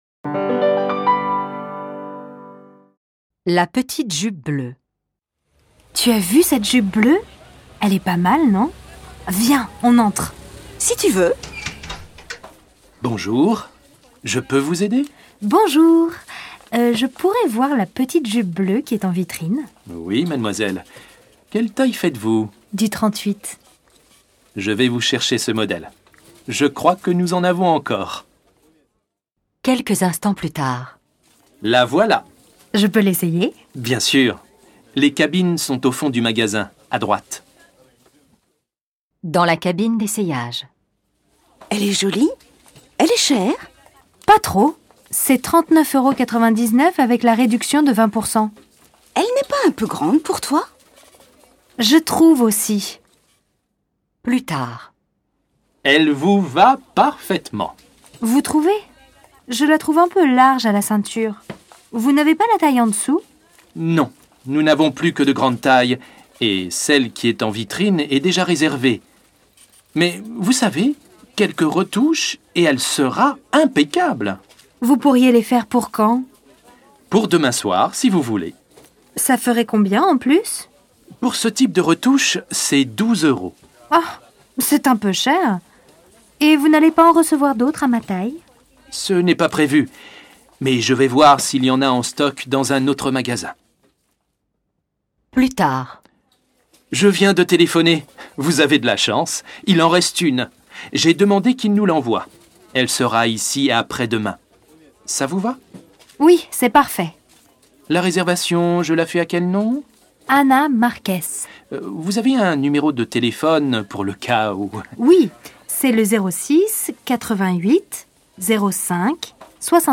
DIALOGUE AU MAGASIN